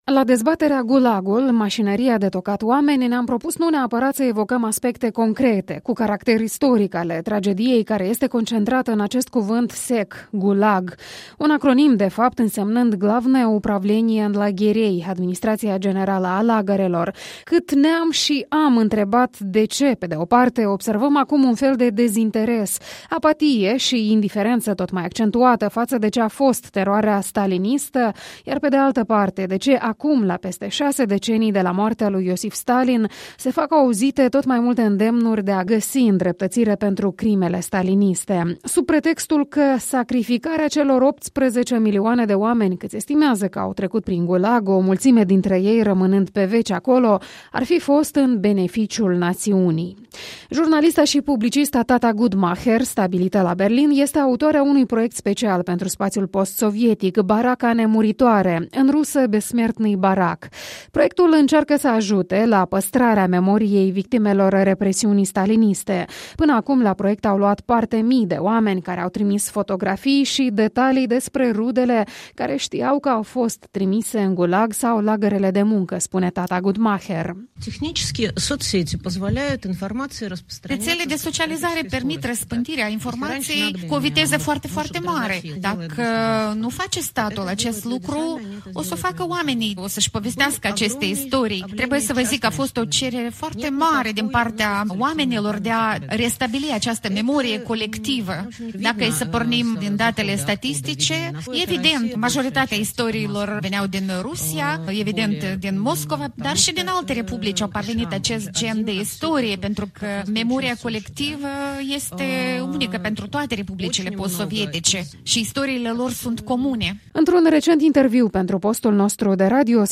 La Școala de arte din Ialoveni a avut loc astăzi dezbaterea „Gulagul – mașinăria de tocat oameni”, parte a proiectului Europei Libere intitulat „Antinostalgia – privind spre viitor”.